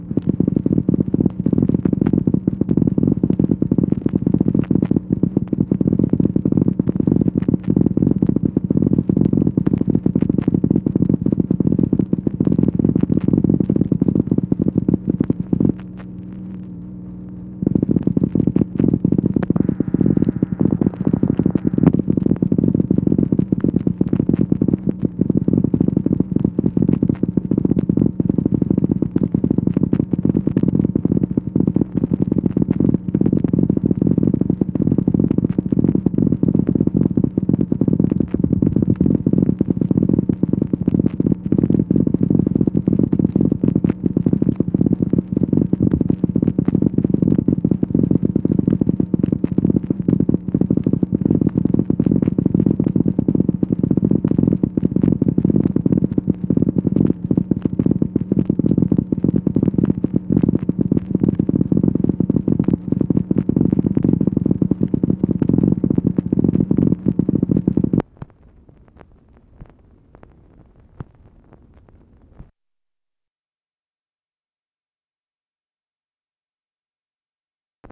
MACHINE NOISE, January 6, 1964